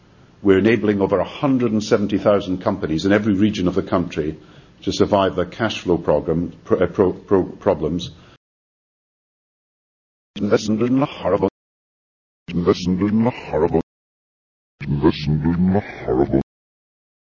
Press Conference with British PM Gordon Brown